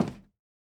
added stepping sounds
Flats_Metal_Grate_005.wav